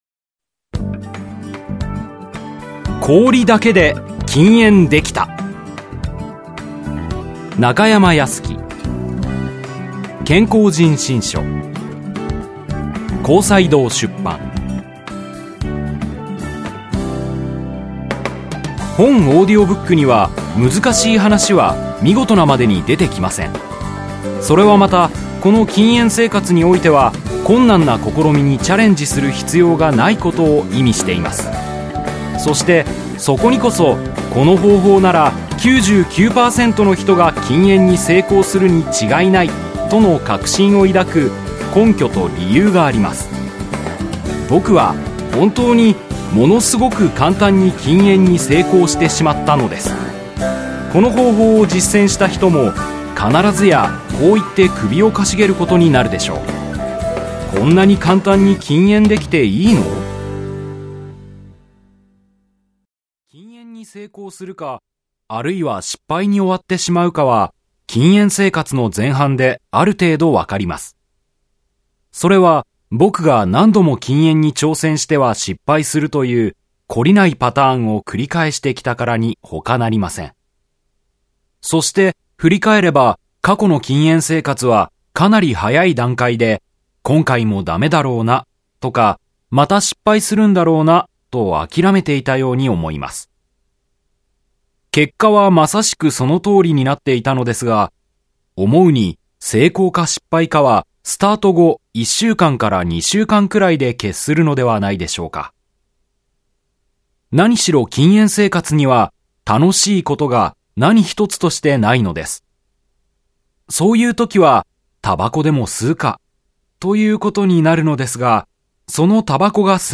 [オーディオブックCD] 氷だけで禁煙できた!